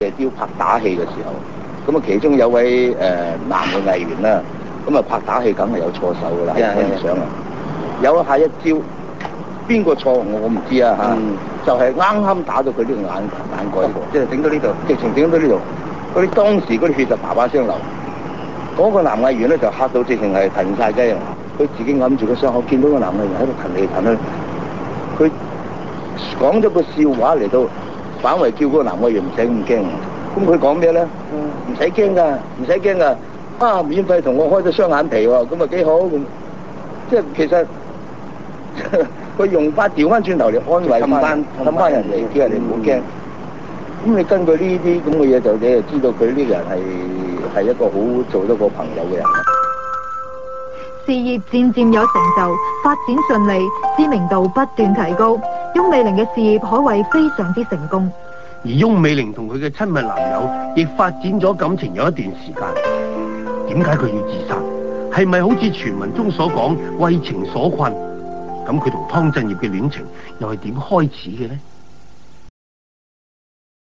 本影片回顧了一些翁美玲生前的片段，和訪問